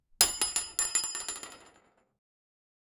a-big-metal-pillar-fall-d4fb4uxm.wav